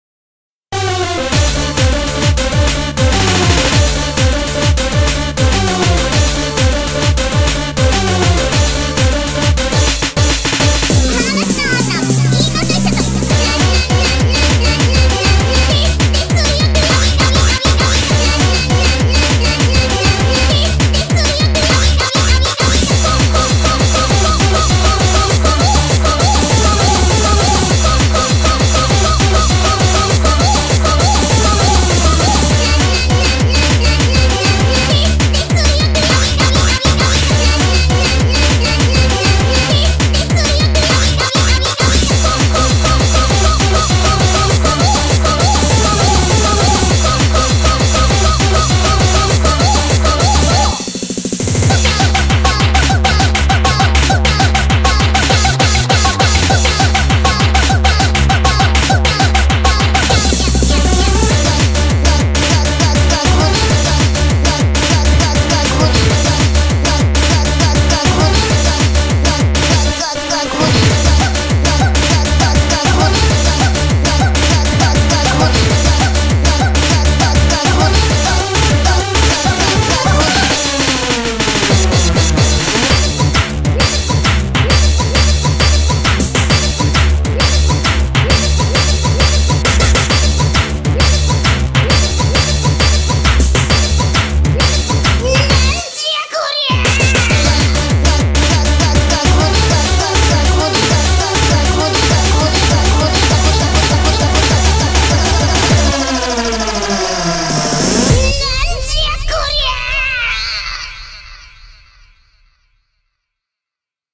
BPM100-800
Audio QualityPerfect (Low Quality)